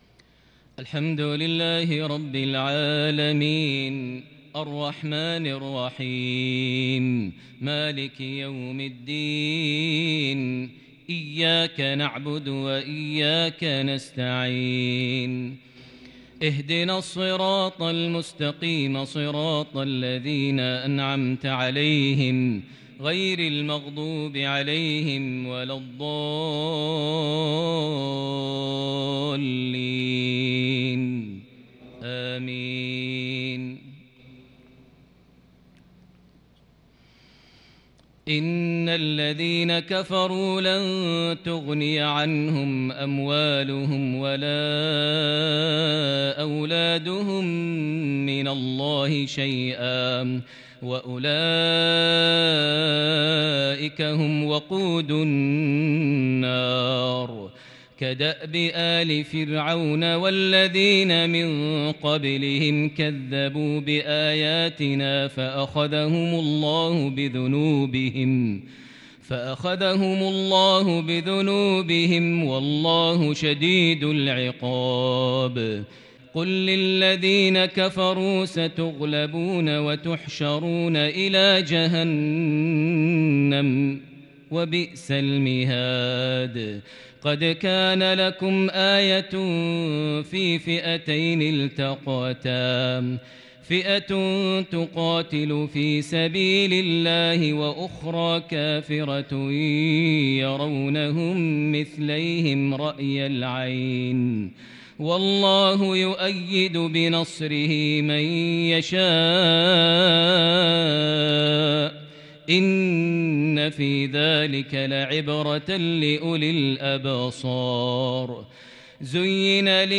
عشائية متألقة فريدة بمزيج الكرد والصبا من سورة آل عمران |الجمعة 27 ذي الحجة 1442هـ > 1442 هـ > الفروض - تلاوات ماهر المعيقلي